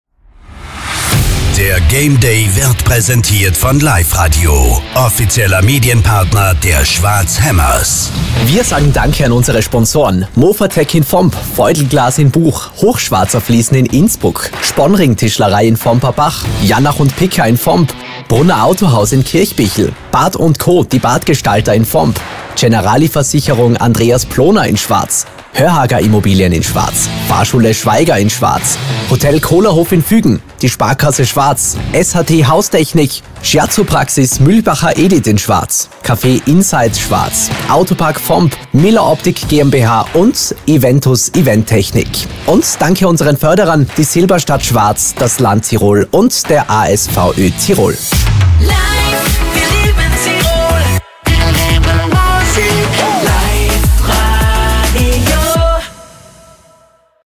Werbebeispiele
Werbedurchsagen
Werbedurchsagen-Neu.m4a